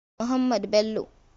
pronunciation was an Emir of Kano who reigned from 1883 to 1892.[1][2]
Ha-Muhammad_Bello.ogg.mp3